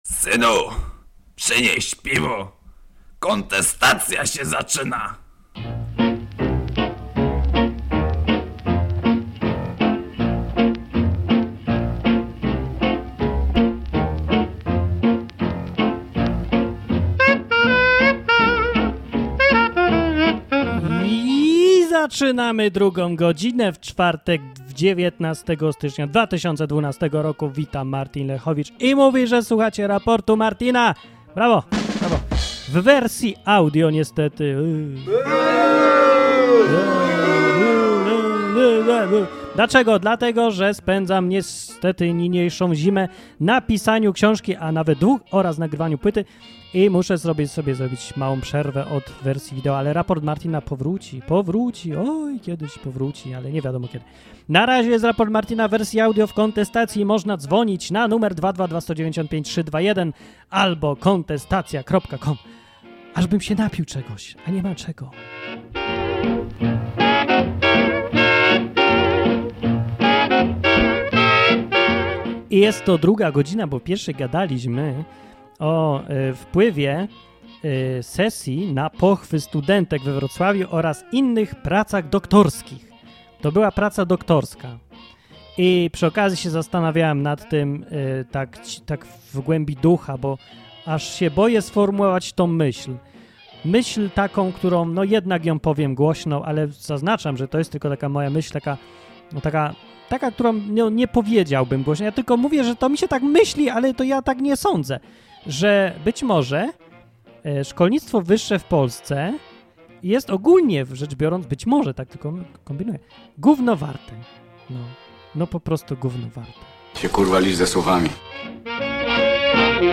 Były informacje, komentarze, słuchacze, wszystko co najlepsze.